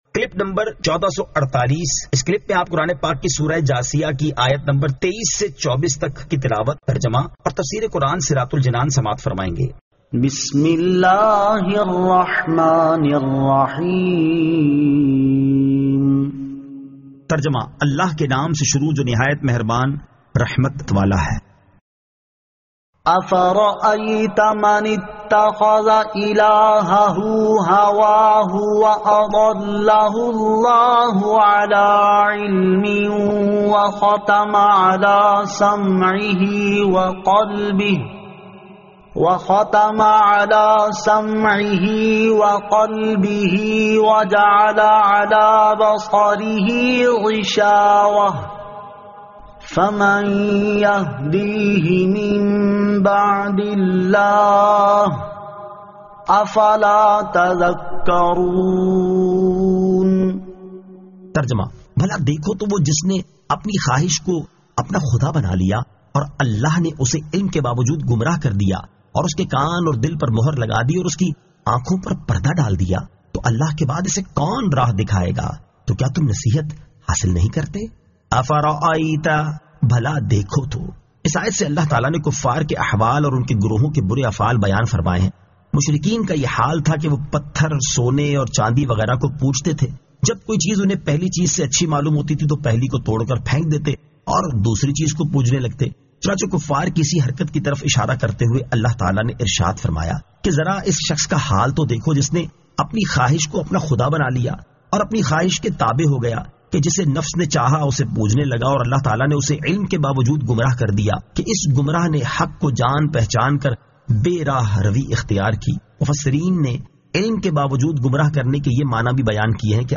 Surah Al-Jathiyah 23 To 24 Tilawat , Tarjama , Tafseer